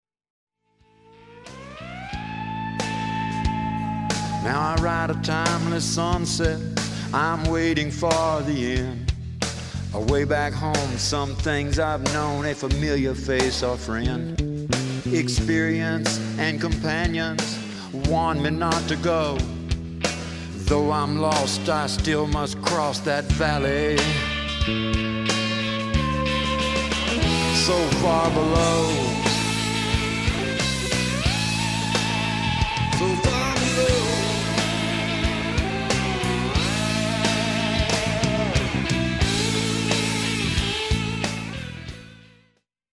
lap steel guitar